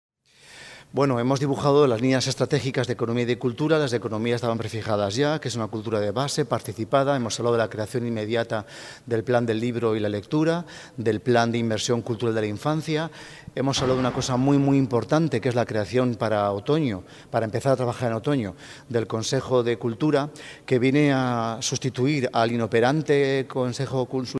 Escuche aquí al Consejero resumiendo las prioridades en el Área de Cultura: